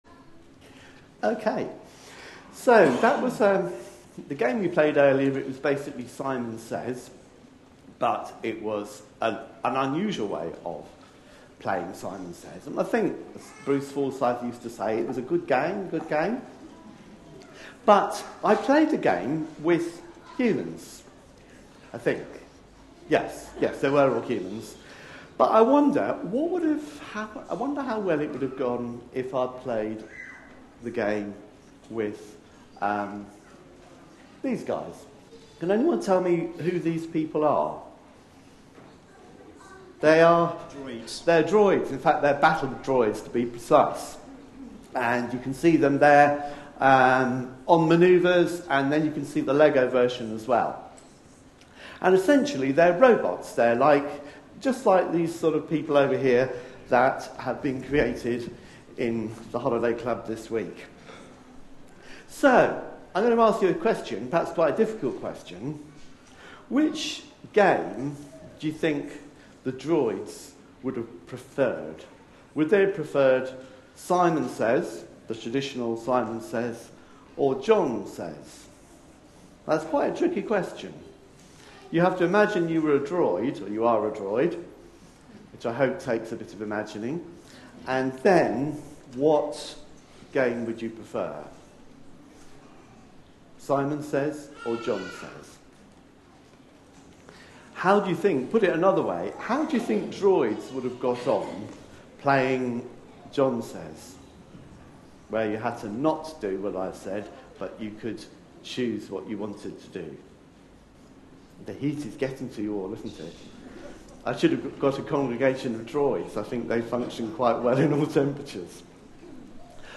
A sermon preached on 3rd August, 2014, as part of our On The Beach. series.